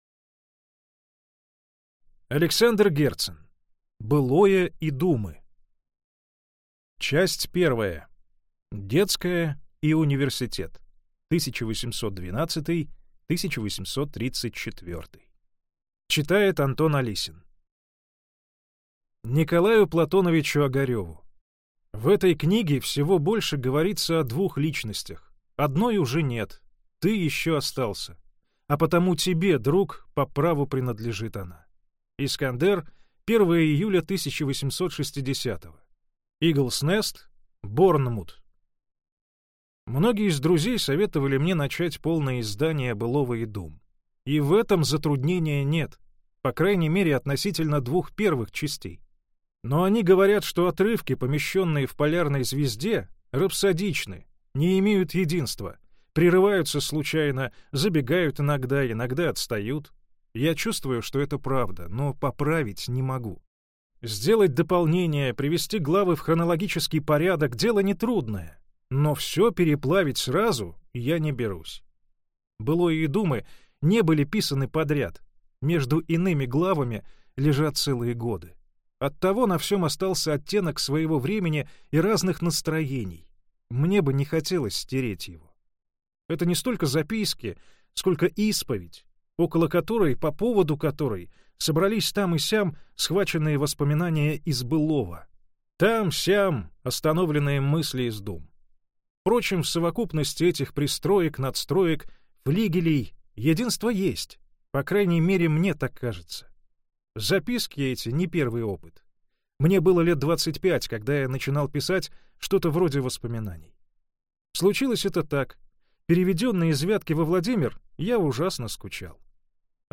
Аудиокнига Былое и думы. Детская и университет. Тюрьма и ссылка (Часть 1) | Библиотека аудиокниг